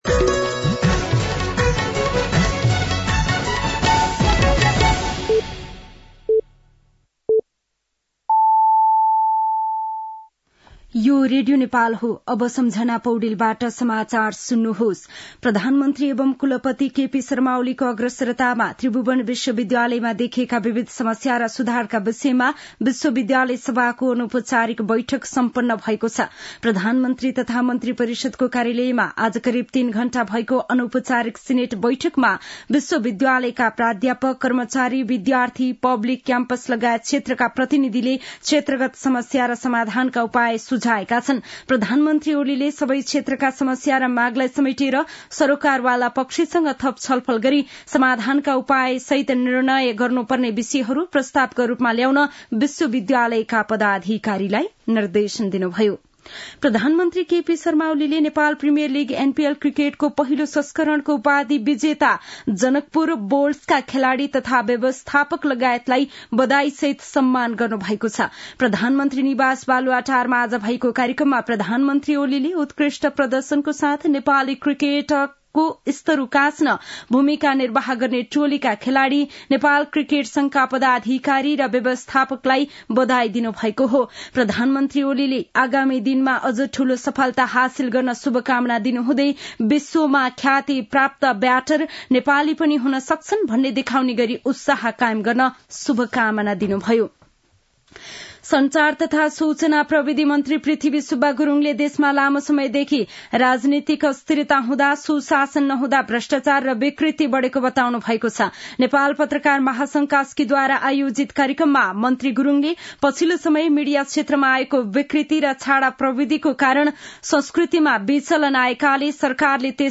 दिउँसो ४ बजेको नेपाली समाचार : ८ पुष , २०८१
4-pm-nepali-news-4.mp3